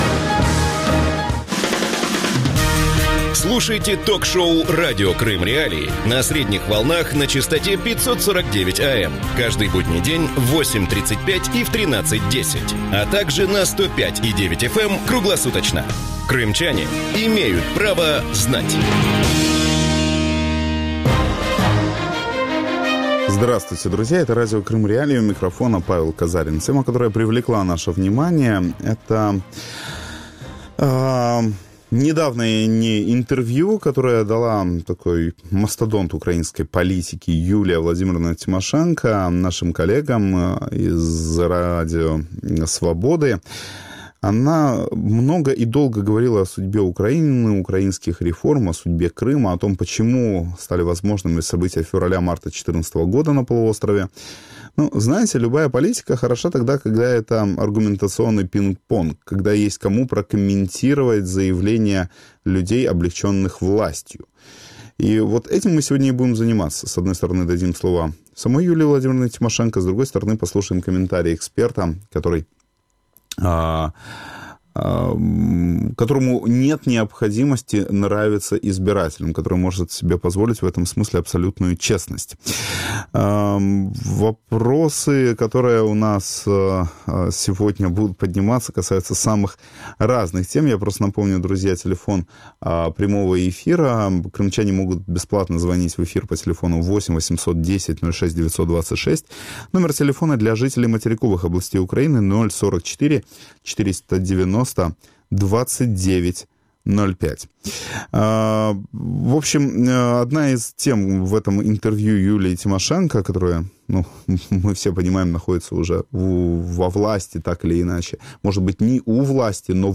Как складываются российско-украинские отношения? Своим видением ситуации в эксклюзивном интервью Радио Свобода в Брюсселе поделилась лидер партии «Батькивщина» Юлия Тимошенко.